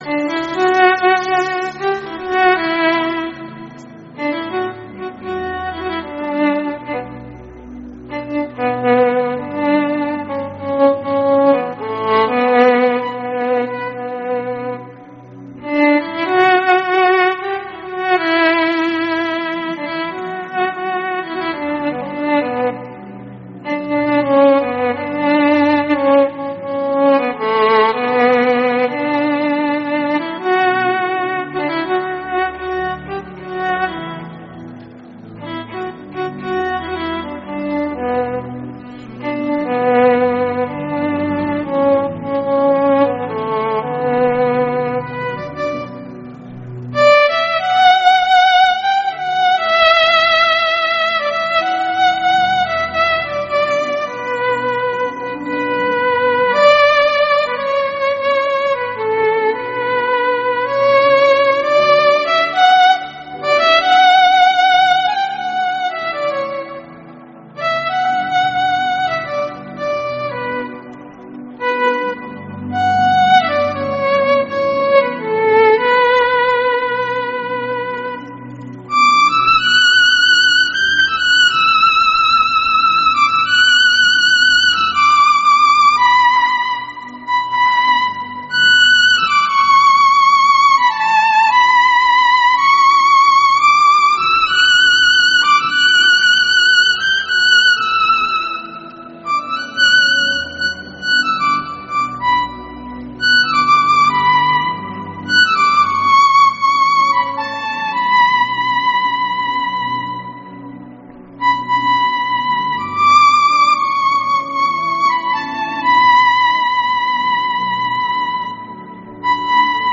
March 2021 Testimony And Thanksgiving Service